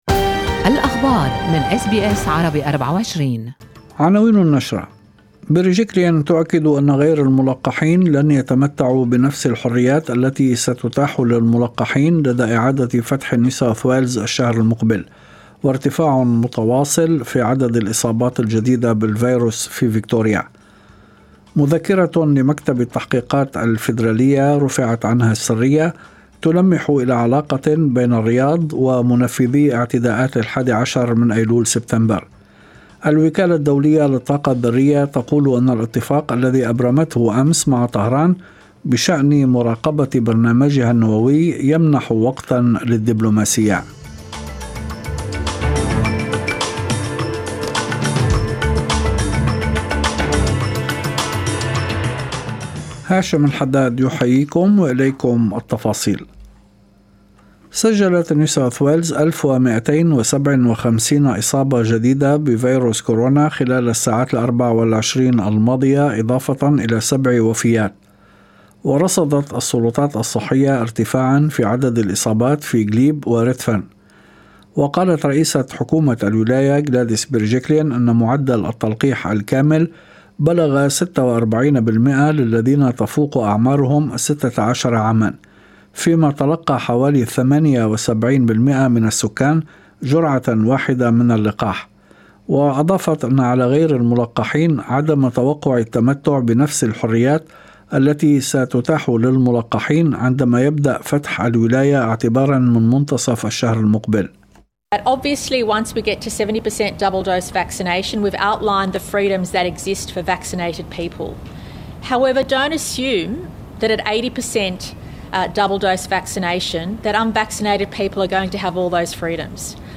نشرة أخبار المساء13/9/2021